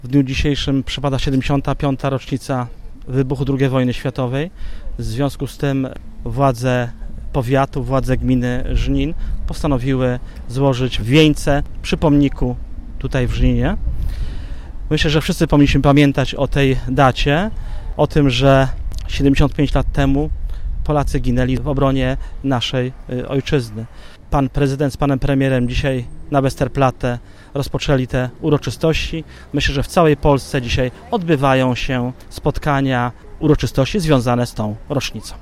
Również władze gminy i powiatu złożyły kwiaty pod pomnikiem Walki i Męczeństwa na skwerze przy ul. Kościuszki.